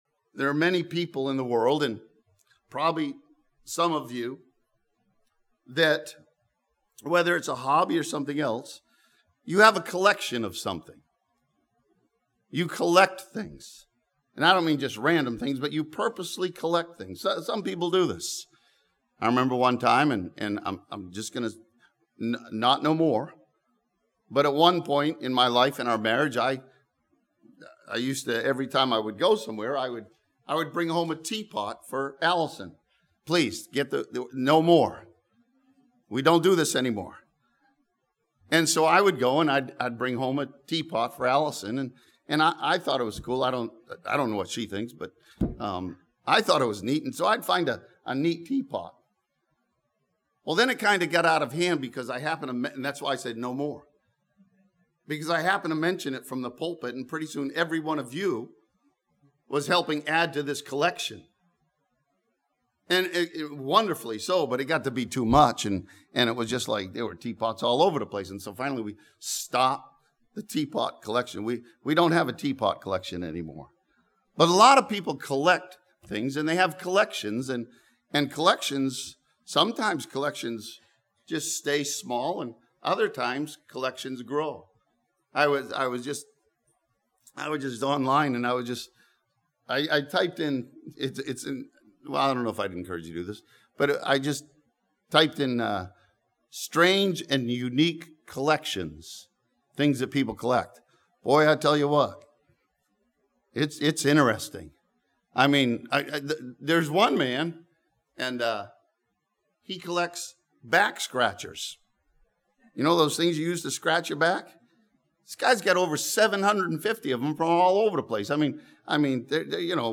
This sermon from John chapter 6 challenges the believer to continue to add to the growing collection of their faith.